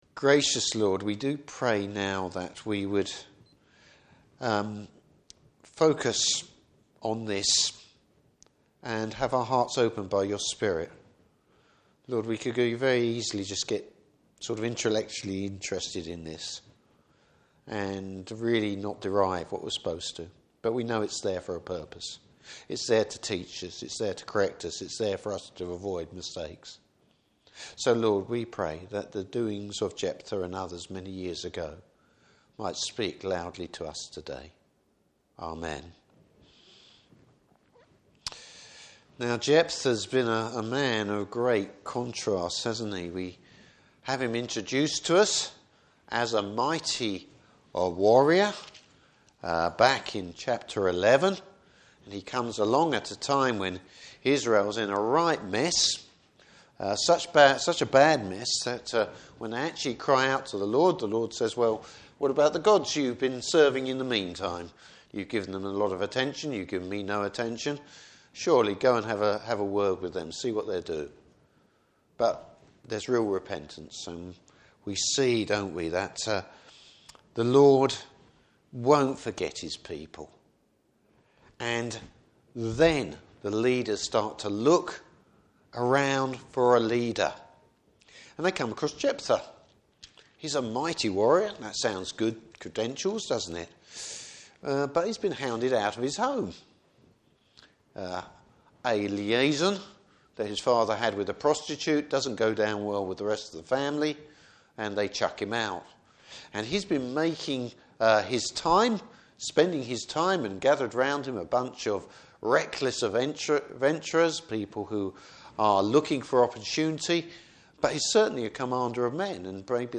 Service Type: Evening Service Bible Text: Judges 12.